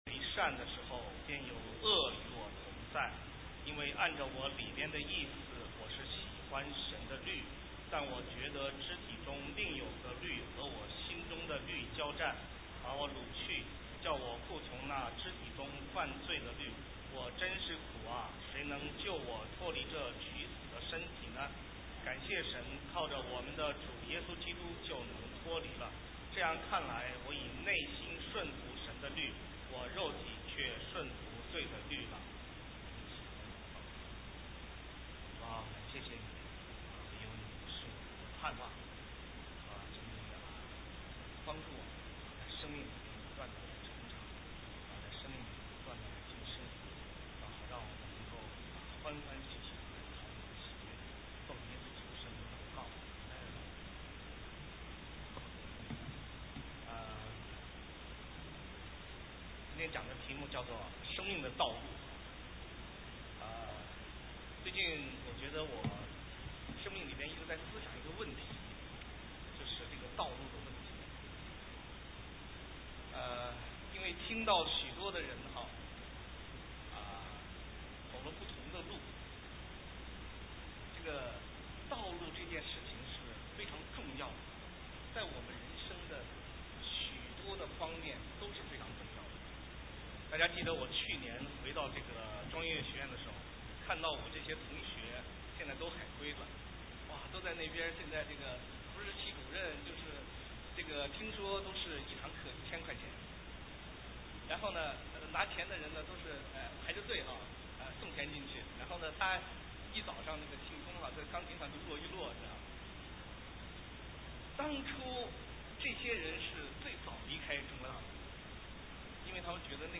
神州宣教--讲道录音 浏览：生命的道路 (2011-08-28)